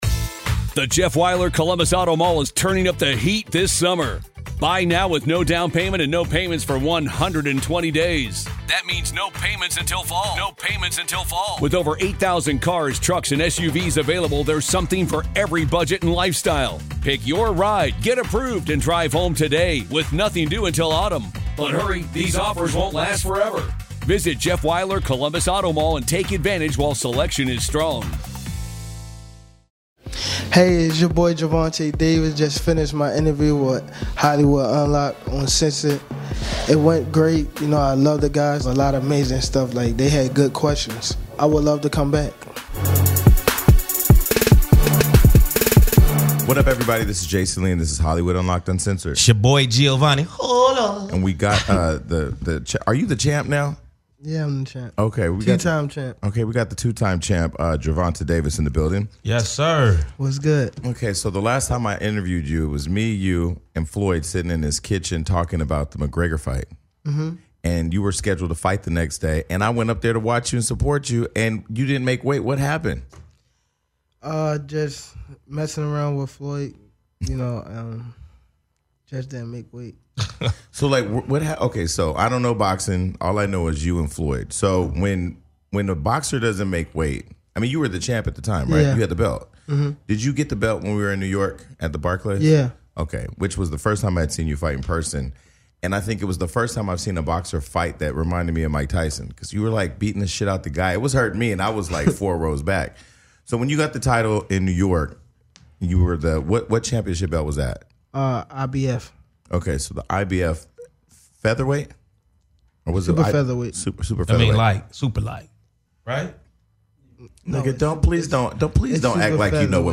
This week, the hosts are joined by boxing champ Gervonta Davis!